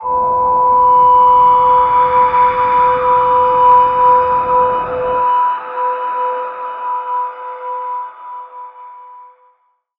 G_Crystal-B5-f.wav